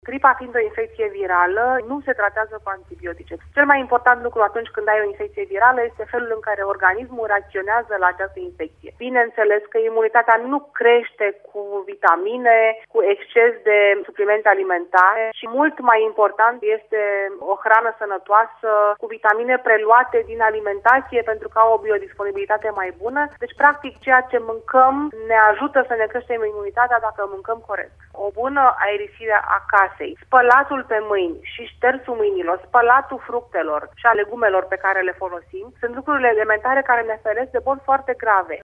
Medicul de familie